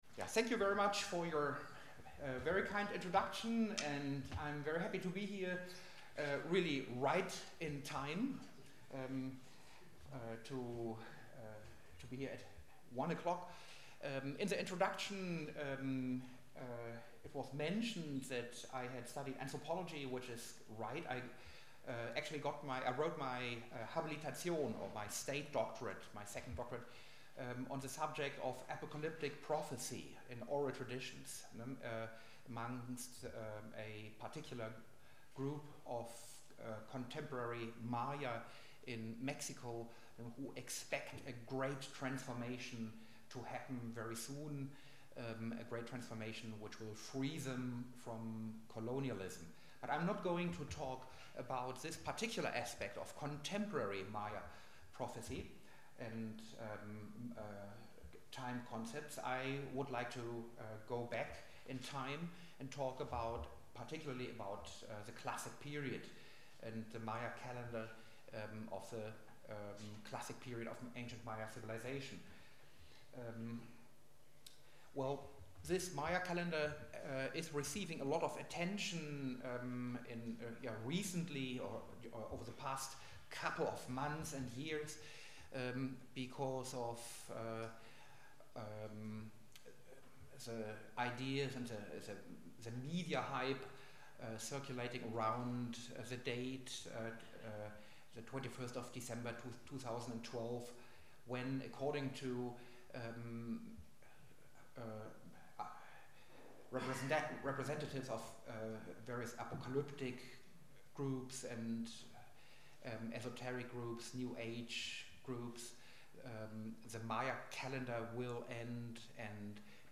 Lecture Series Winter 2012-2013